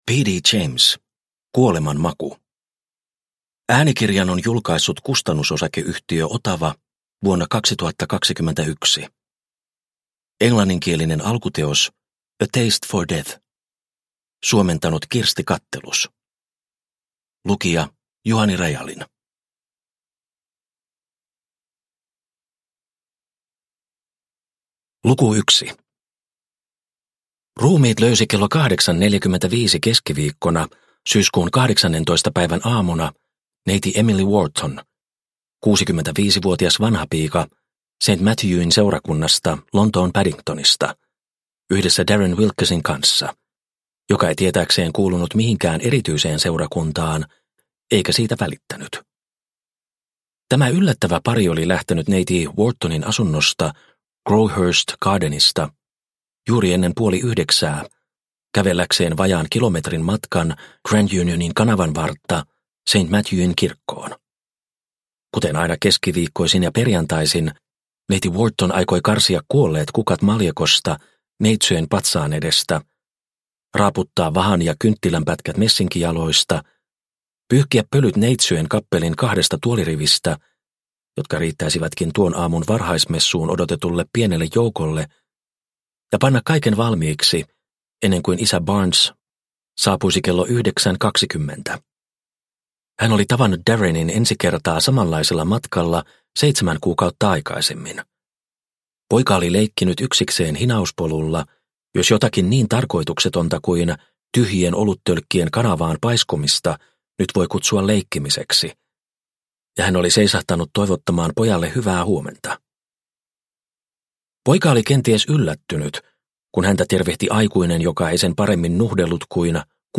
Kuoleman maku – Ljudbok – Laddas ner